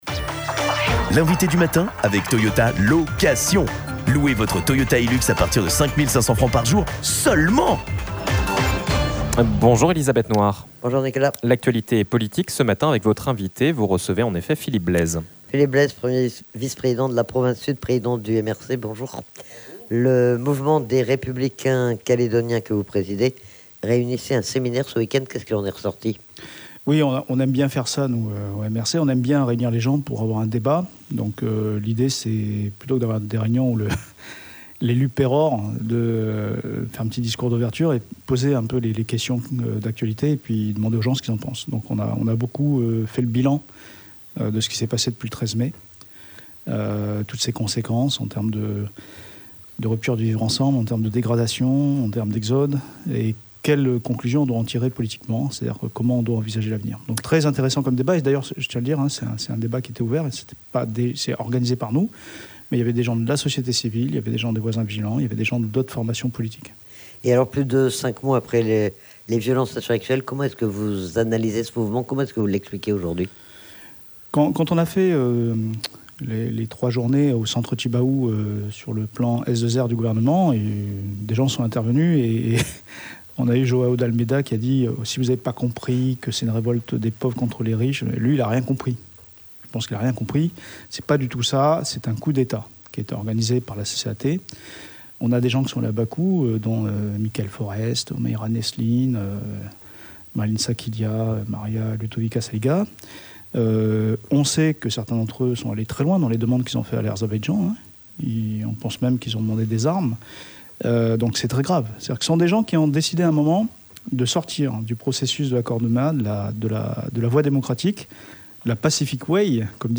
Le point sur l'actualité avec Philippe Blaise, premier vice-président de la Province Sud et président du MRC, le parti qui se réunissait ce weekend pour faire l'état des lieux de la situation calédonienne et dresser les perspectives de reprises des discussions sur l'avenir institutionnel.